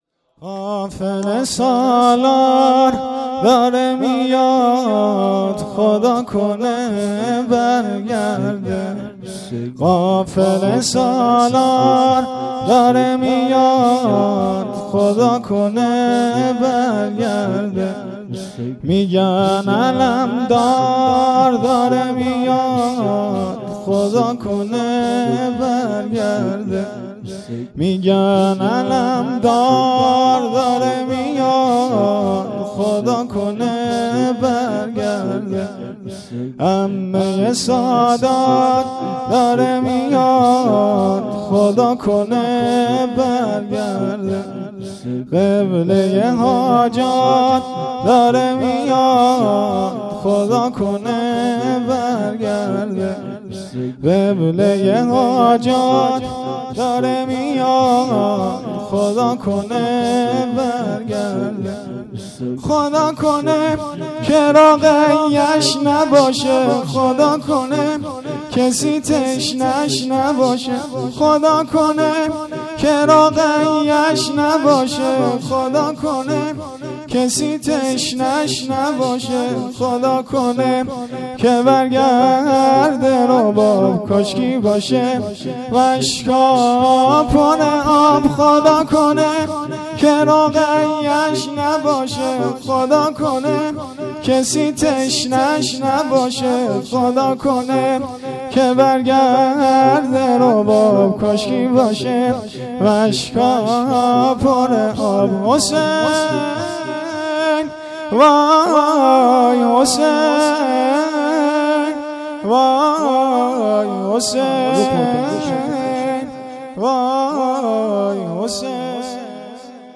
مراسم عزاداری محرم ۱۴۰۴